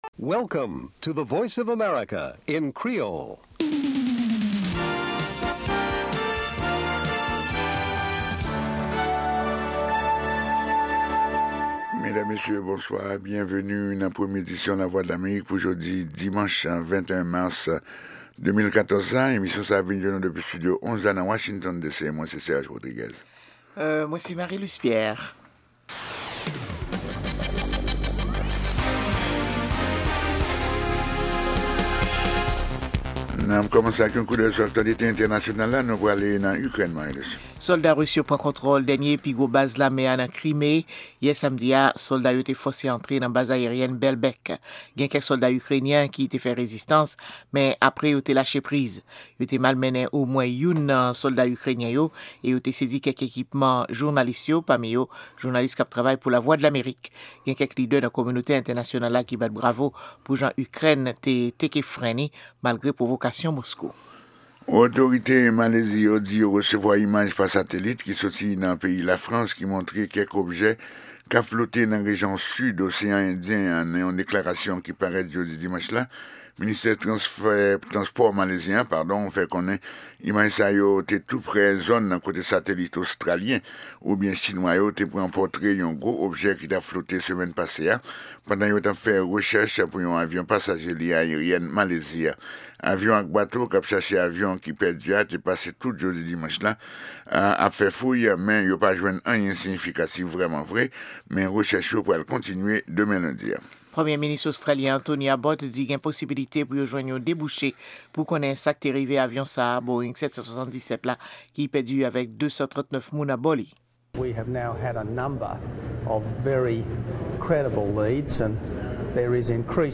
Se 2èm pwogram jounen an, avèk nouvèl tou nèf sou Lèzetazini, Ayiti ak rès mond la. 2 fwa pa semèn (mèkredi ak vandredi) se yon pwogram lib tribin "Dyaloge ak Etazini", sou Ayiti oubyen yon tèm enpòtan konsènan Lèzetazini ou rejyon Amerik Latin nan.